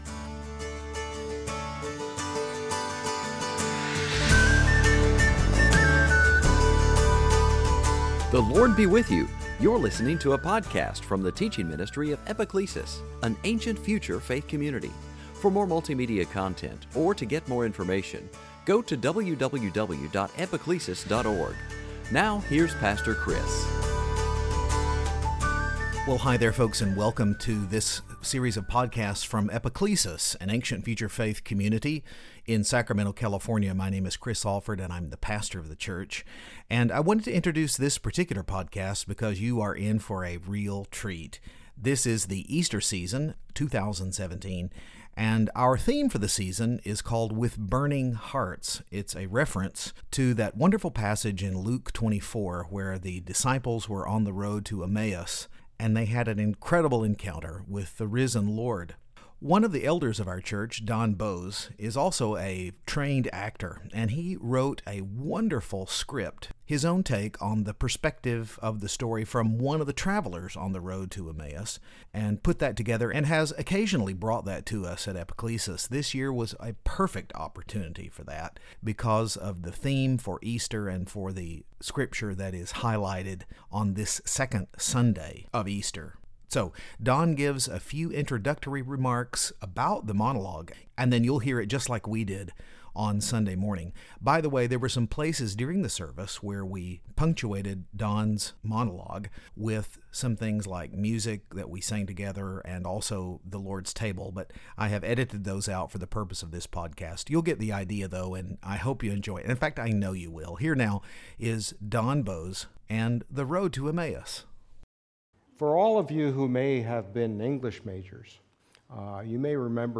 Worshipers at Epiclesis were in for a real treat on the second Sunday of Eastertide this year. We had embarked upon a seasonal theme titled "With Burning Hearts" and came to the passage in Luke's gospel where the travelers on the Road to Emmaus have a remarkable encounter with the living Christ.
wrote a delightful monologue